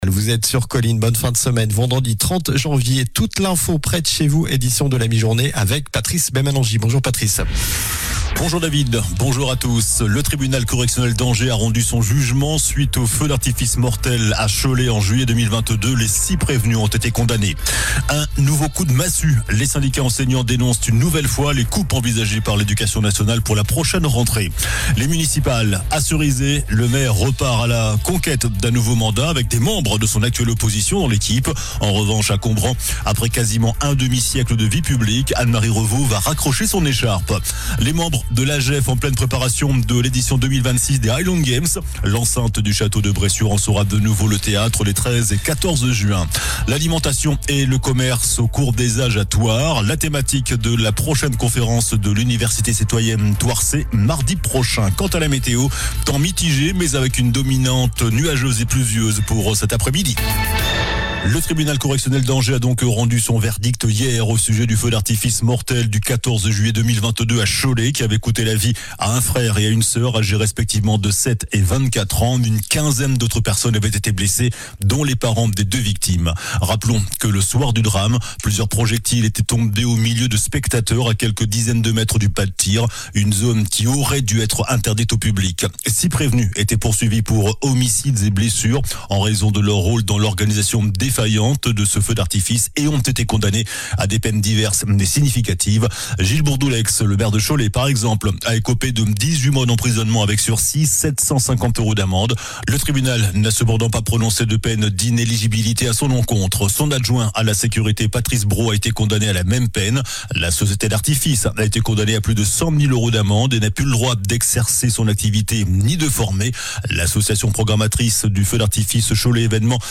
Journal du vendredi 30 janvier (midi)